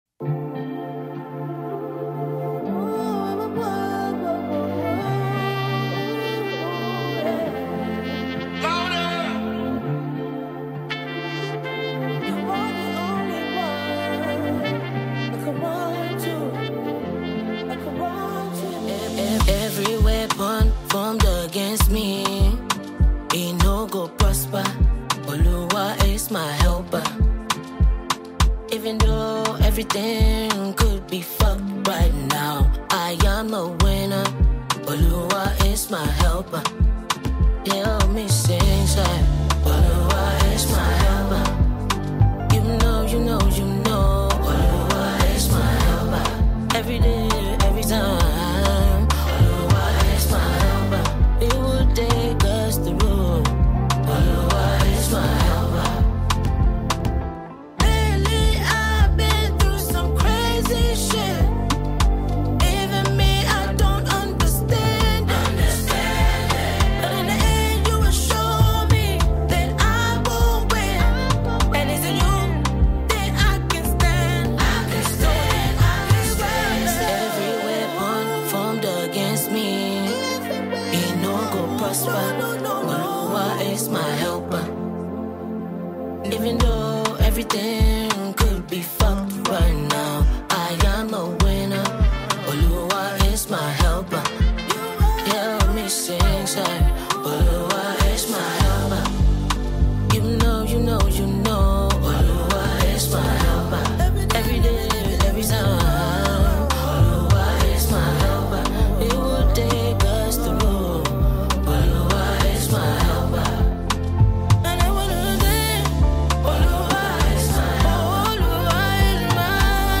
Mp3 Gospel Songs
enchanting melodies
emotive vocal delivery